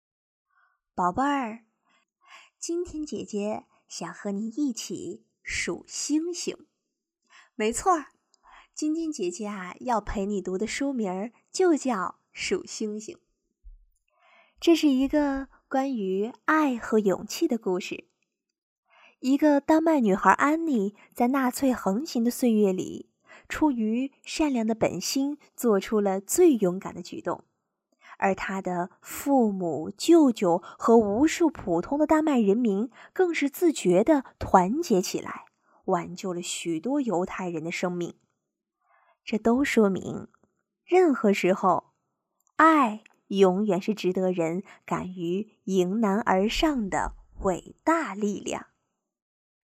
• 8央视女声2号
儿童故事-温柔甜美